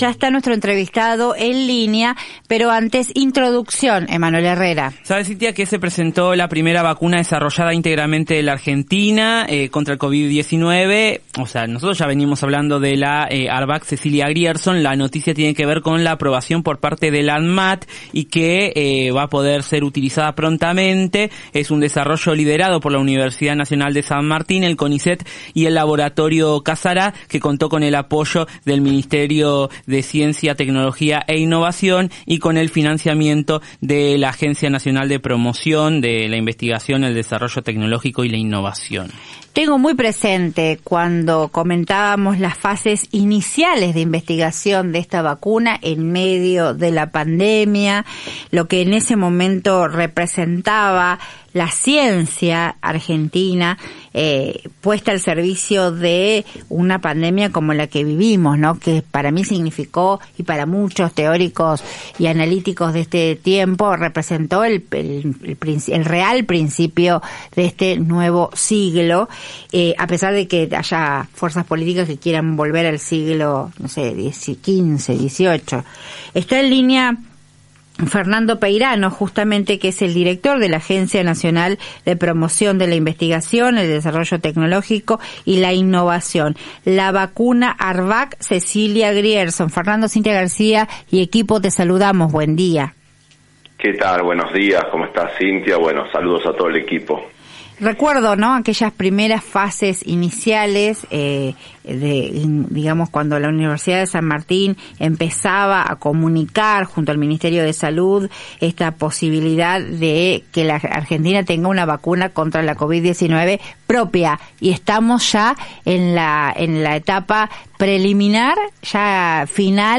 El director de la Agencia Nacional de Promoción de la Investigación, el Desarrollo Tecnológico y la Innovación (I+D+i) dependiente del Ministerio de Ciencia, Tecnología e Innovación, explicó el hito histórico que significa para Argentina contar con una vacuna contra el coronavirus 100 por ciento nacional. En dialogo con La Garcia por AM750.